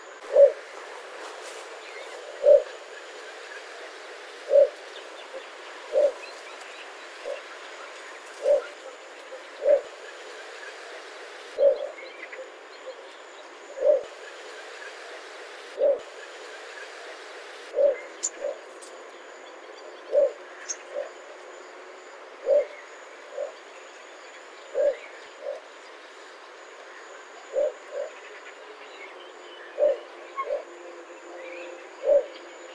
Звуки малой выпи (волчка)